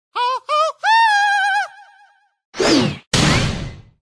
Clash Royale Santa Hog sound effects free download